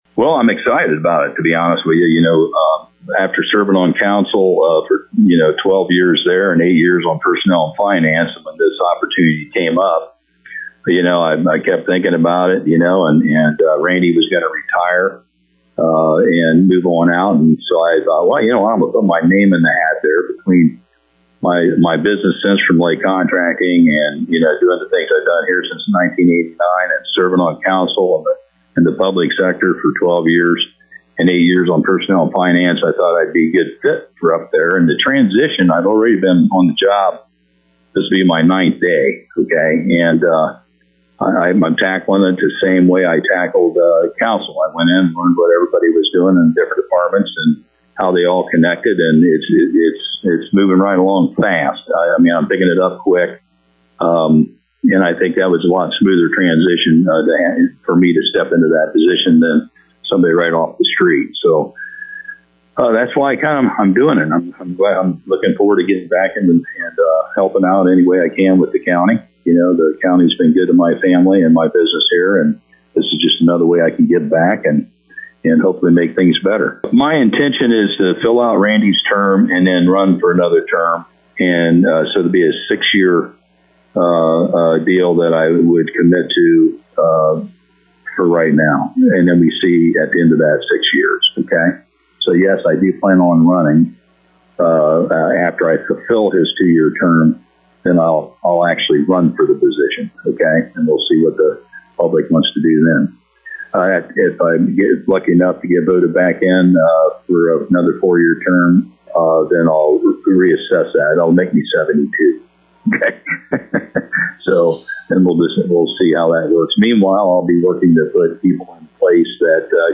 To hear comments with Jeff Larmore: